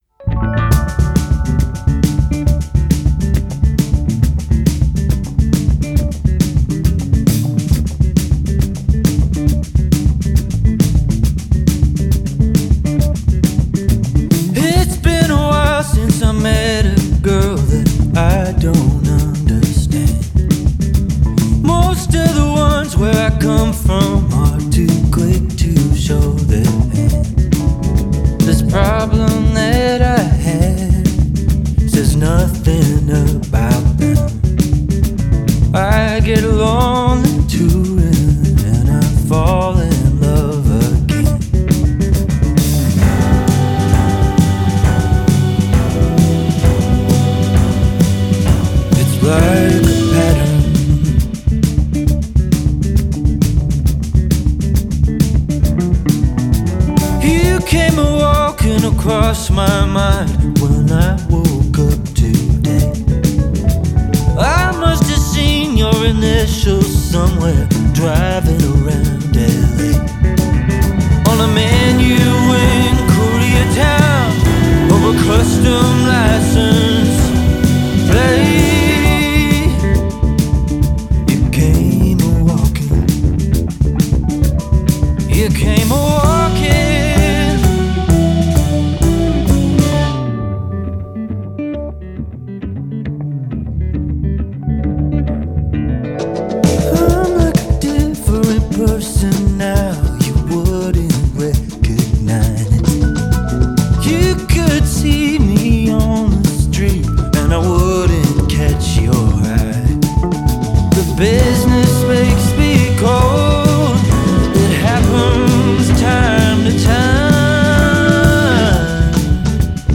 Western Canadian indie music mix